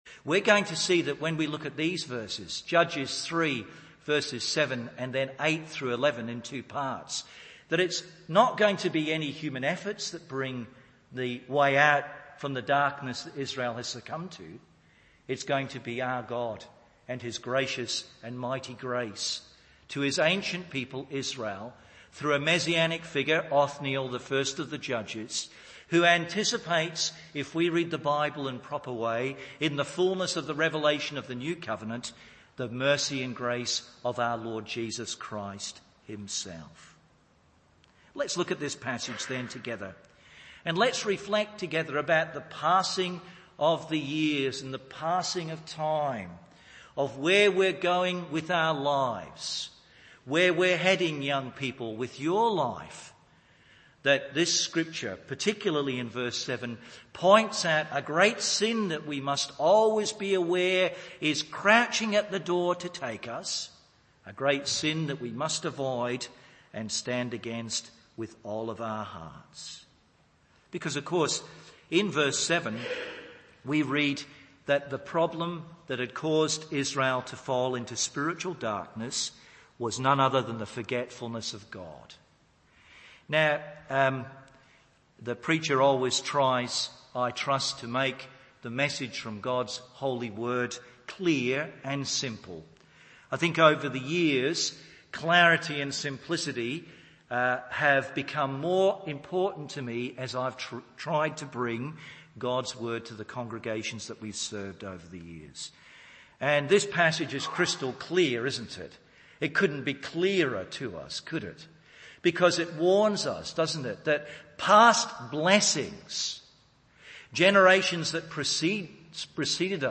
Morning Service Judges 3:7-11…